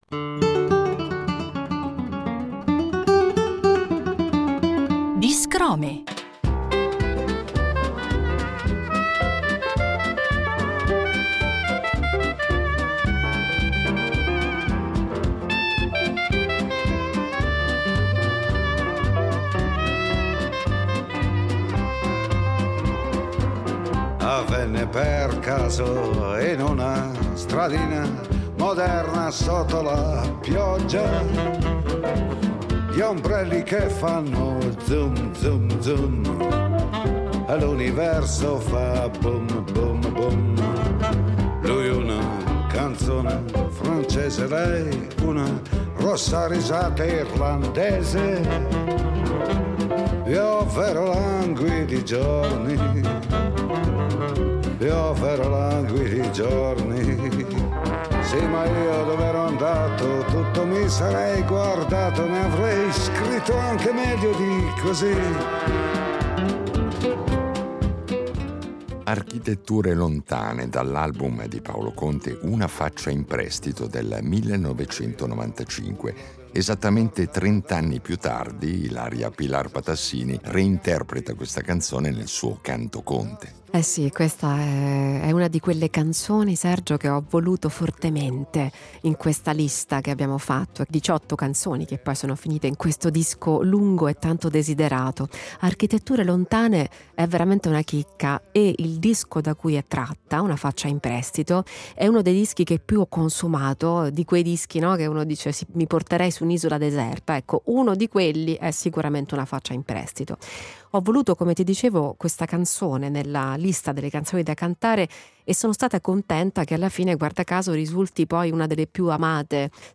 In questa serie di Biscrome ci presenta con parole sue le molte canzoni che ci ha regalato per programmi diversi, oltre al suo recente progetto discografico dal titolo eloquente, Canto Conte, che l’artista descrive non solo come una sfida interpretativa, ma come un vero e proprio «bisogno», «una necessità intima personale, oltre che artistica».